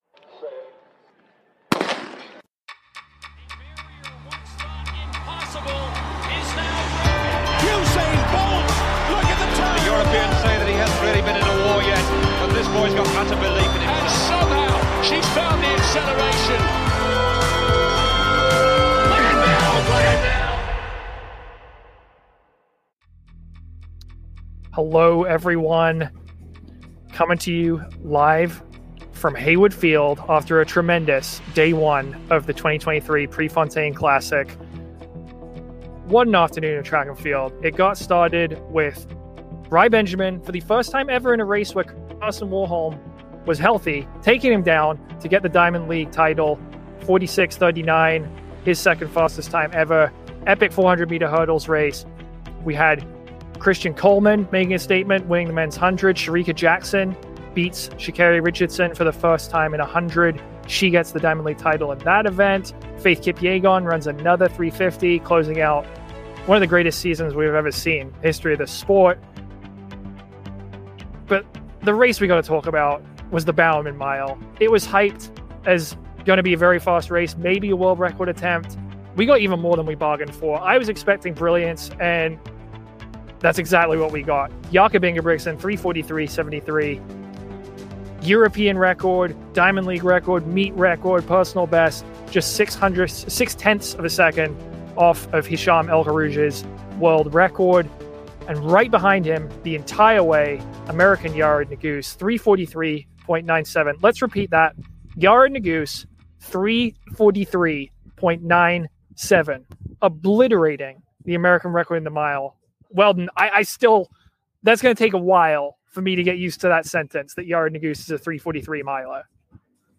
Live podcast from Pre.